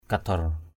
/ka-d̪ɔr/ (d.) bánh đúc = espèce de gâteau. tuh kadaor t~H k_d<R đổ bánh đúc.